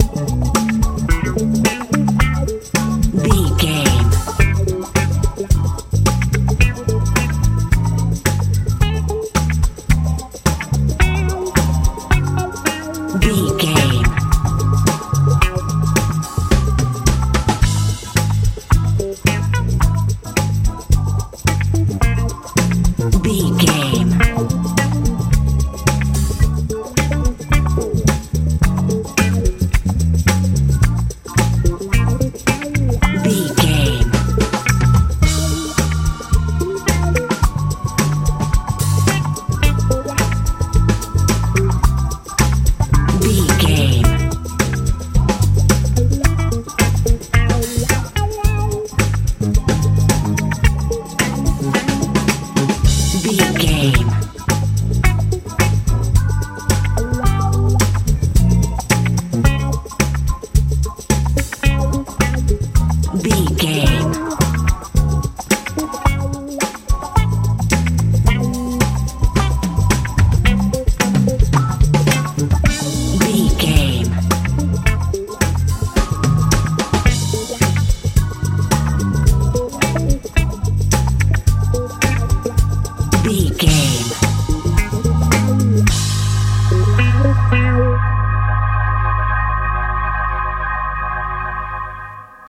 funk
Ionian/Major
F♯
groovy
funky
organ
electric guitar
bass guitar
drums
lively
joyful
playful